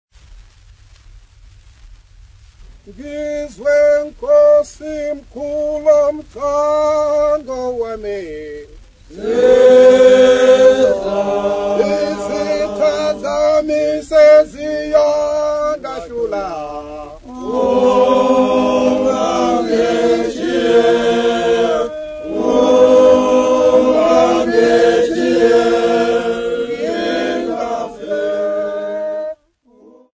Folk Music
Field recordings
Africa, Sub-Saharan
field recordings
Indigenous music